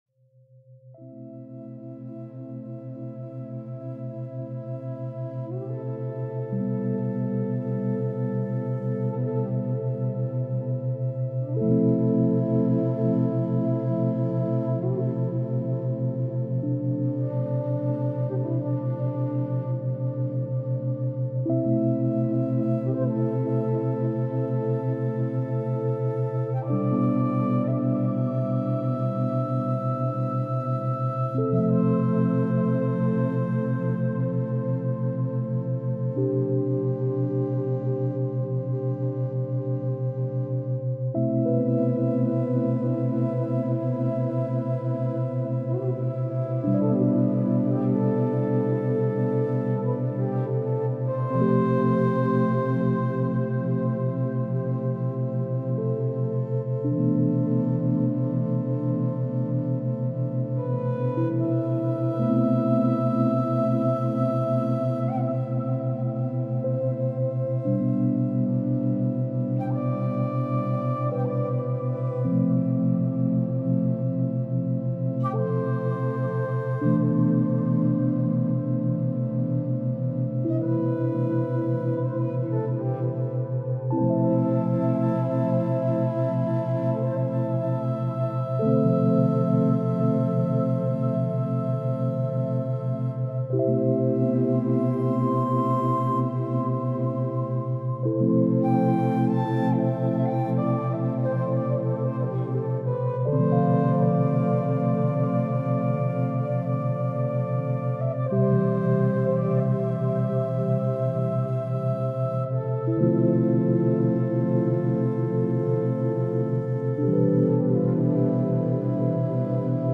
Serotonin Boost – Alpha Brainwaves for Relaxation and Mood Elevation